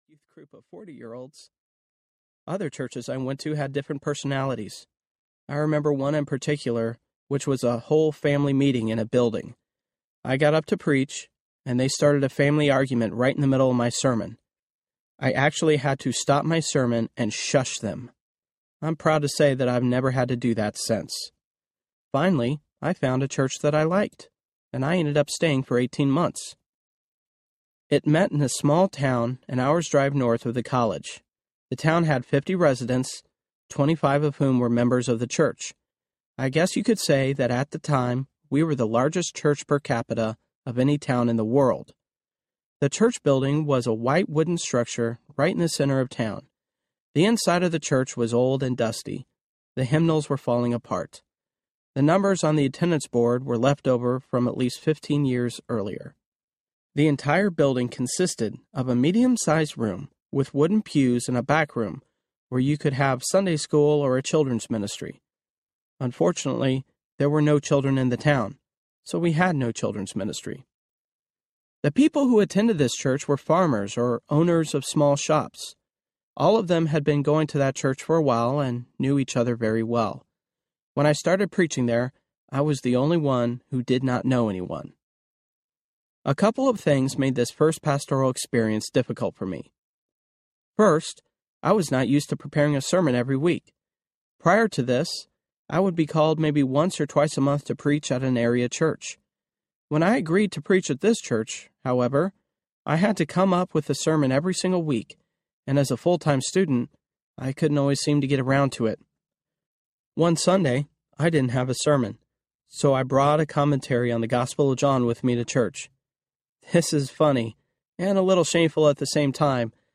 Messy Grace Audiobook
Narrator
6.05 Hrs. – Unabridged